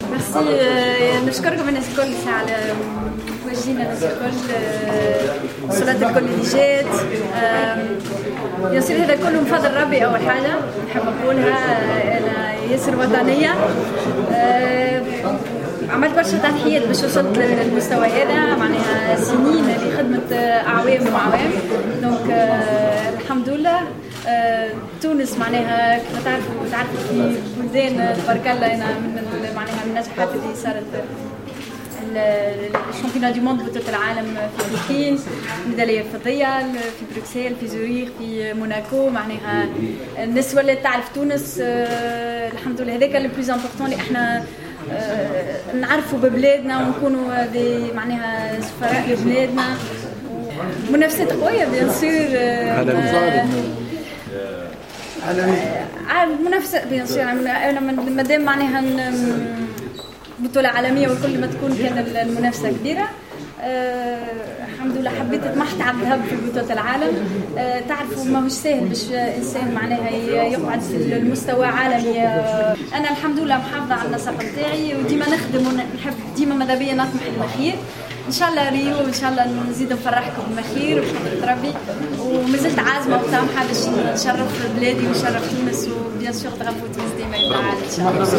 استقبال شعبي و رسمي للعداءة حبيبة الغريبي في مطار قرطاج